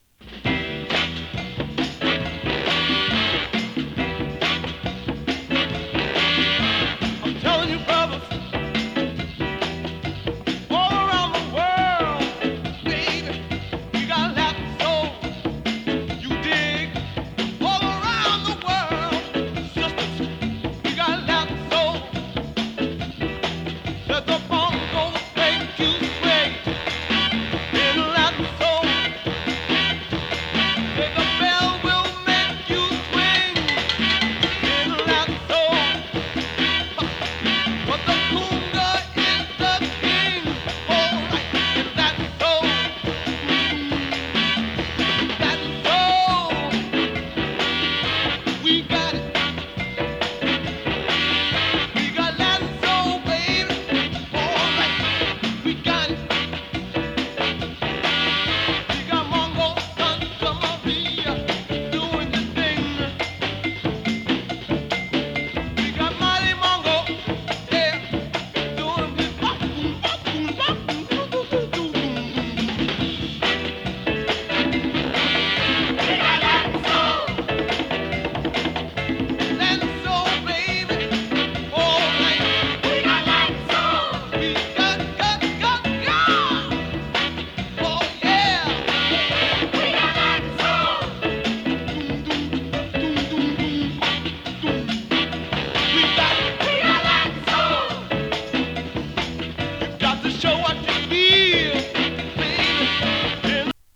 Voc入り 猥雑 ブーガルー